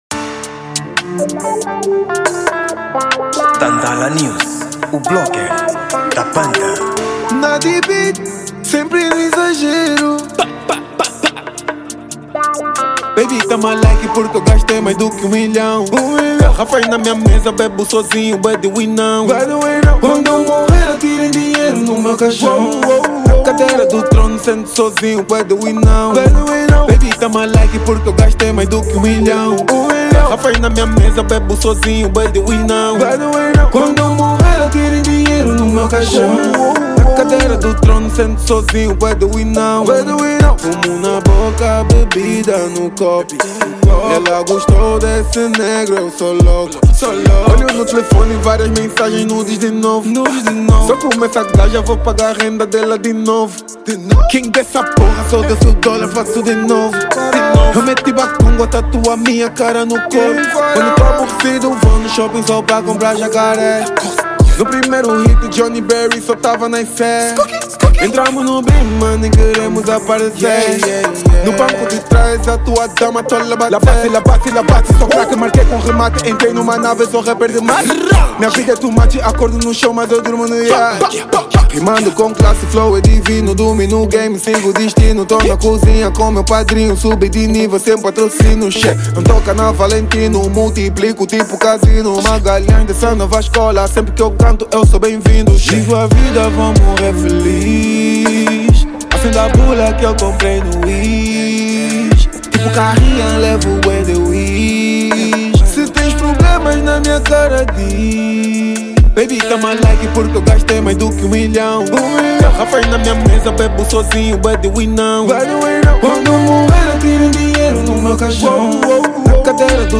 Gênero: Trap